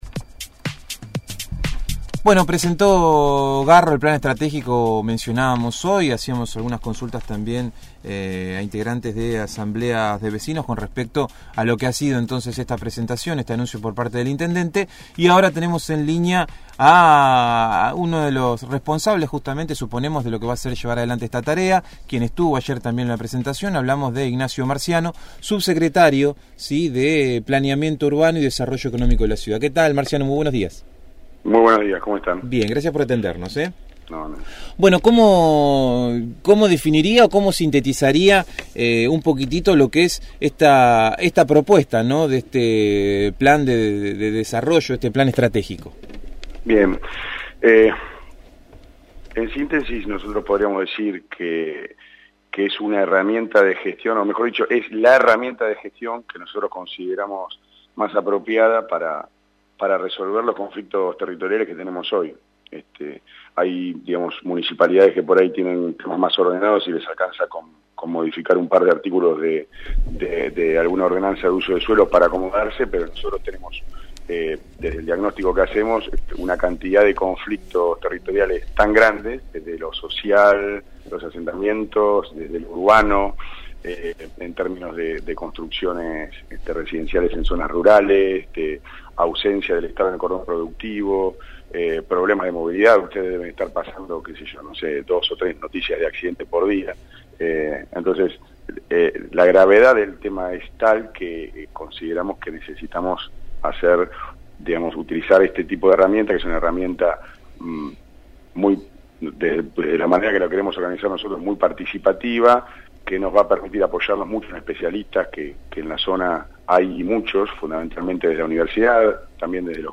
Ignacio Marciano, Subsecretario de Planeamiento y Desarrollo de la Municipalidad de La Plata, dialogó con el equipo de «El Hormiguero» sobre el plan estratégico para la ciudad que presentó en el día de ayer el intendente Julio Garro.